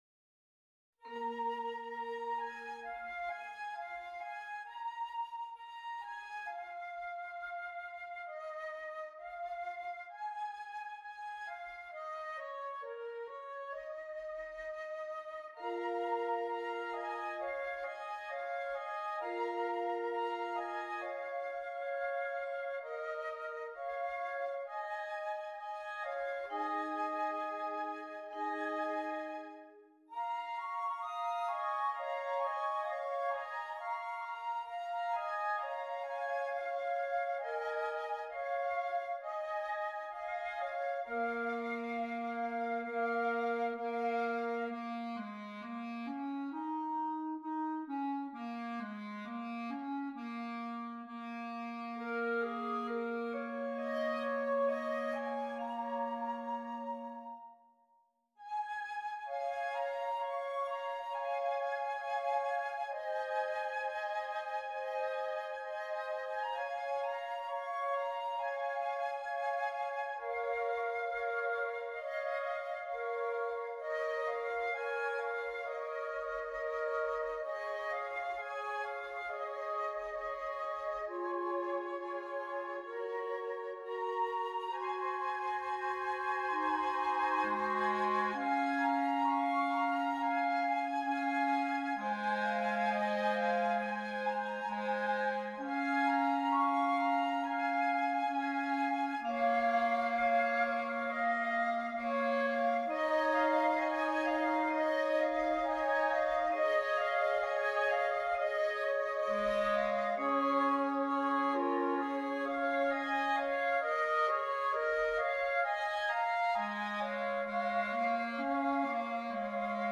2 Flutes, 2 Clarinets